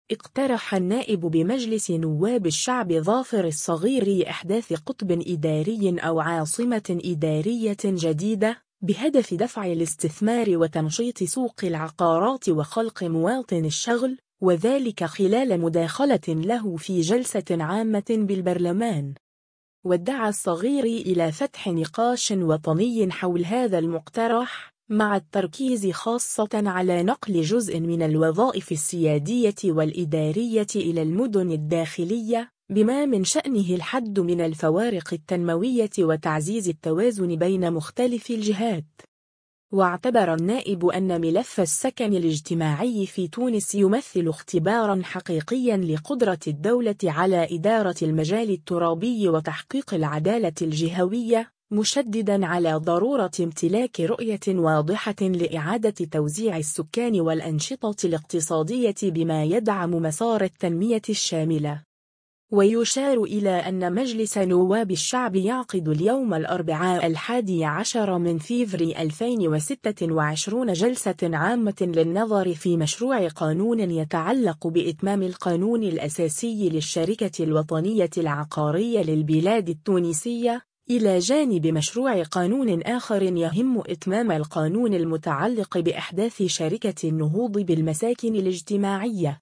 اقترح النائب بمجلس نواب الشعب ظافر الصغيري إحداث قطب إداري أو عاصمة إدارية جديدة، بهدف دفع الاستثمار وتنشيط سوق العقارات وخلق مواطن الشغل، وذلك خلال مداخلة له في جلسة عامة بالبرلمان.